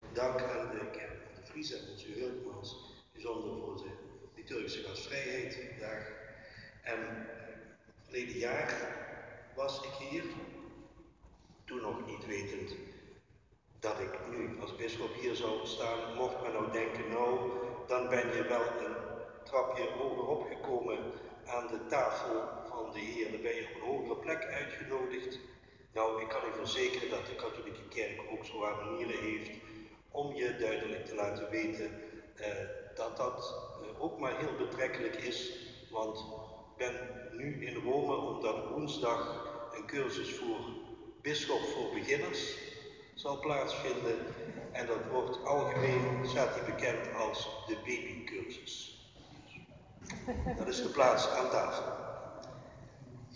Slotwoord Bisschop Smeets
slotwoord-bisschop-Smeets.-1.mp3